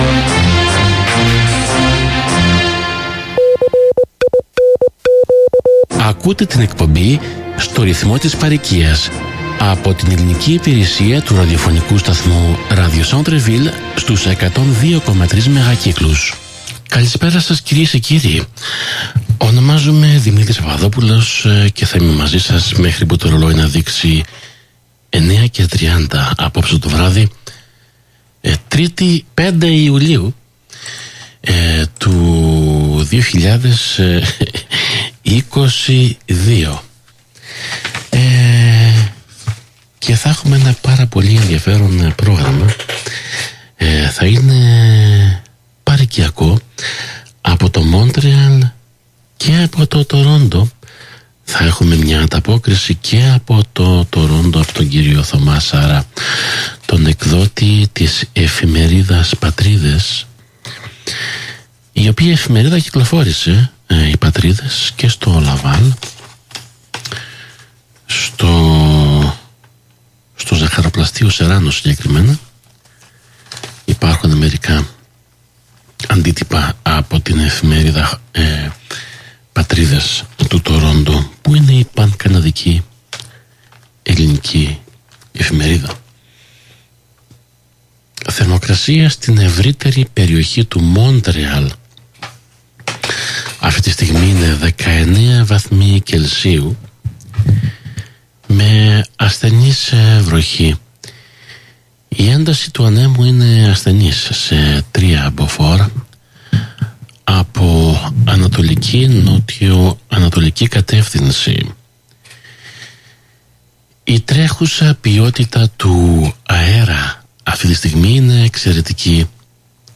(Audio) Ανταπόκριση από την 1η Συνεδρίαση του Νέου Δ/Σ της ΕΚΜΜ
Στο ελληνικό πρόγραμμα του Radio Centre-Ville Montreal 102.3 FM STEREO, την Τρίτη 5 Ιουλίου 2022,